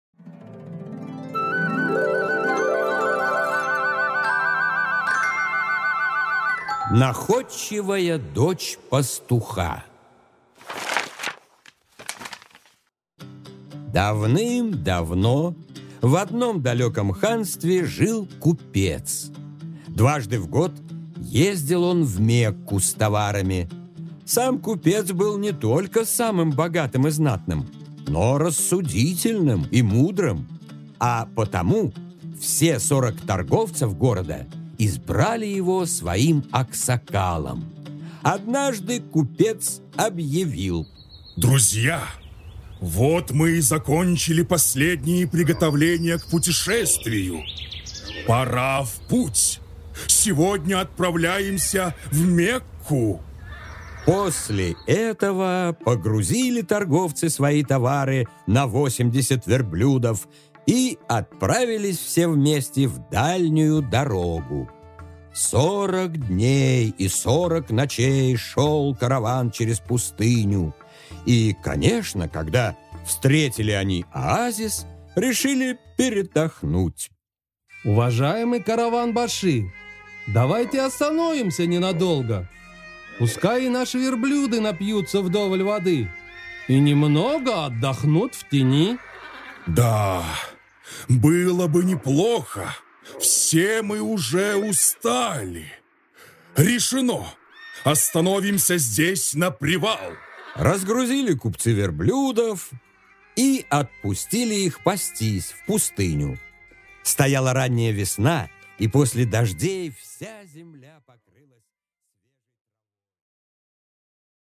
Аудиокнига Находчивая дочь пастуха | Библиотека аудиокниг